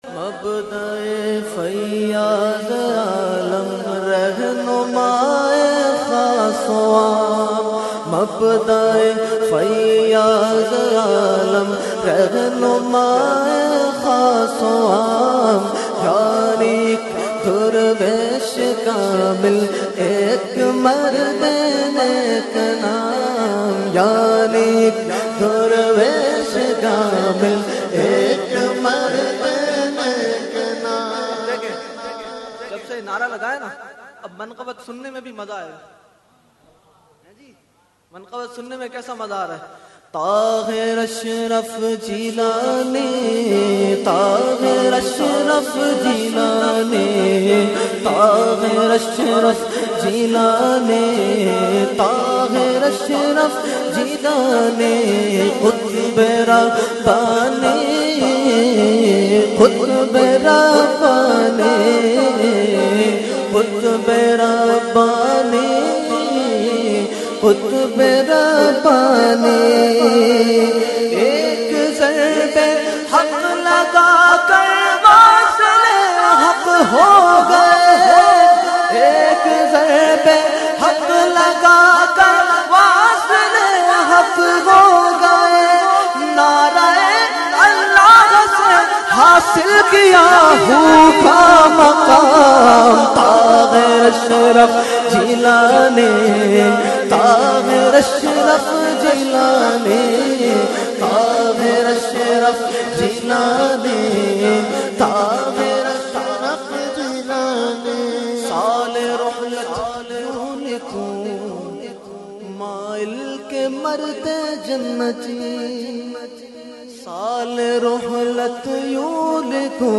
held on 1,2,3 January 2021 at Dargah Alia Ashrafia Ashrafabad Firdous Colony Gulbahar Karachi.
Category : Manqabat | Language : UrduEvent : Urs Qutbe Rabbani 2021